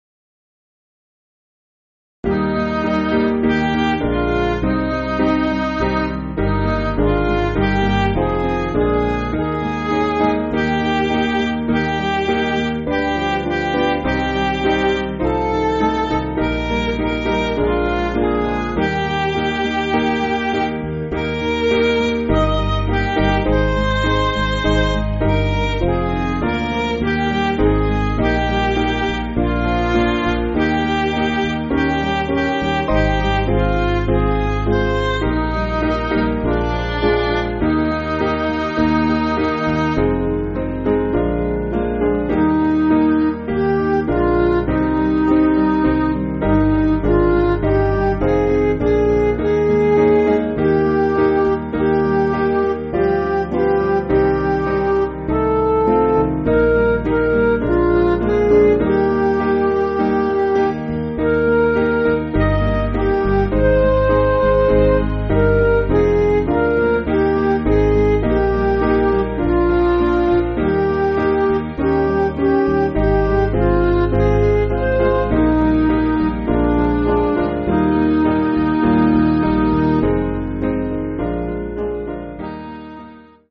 Piano & Instrumental
(CM)   4/Eb